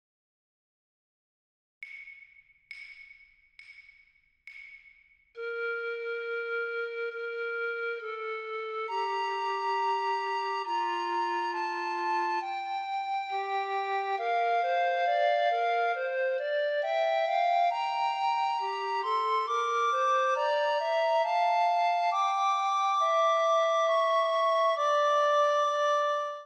S/A(T) recorders
Manchicourt’s duet, posted here, distills the chanson’s emotional core into an intimate dialogue between two voices. His setting preserves the lyrical sorrow of Sandrin’s original while introducing gentle contrapuntal interplay, inviting performers to explore expressive nuance and ensemble empathy.
you play alto Sound Sample Download score/parts Composition Share Link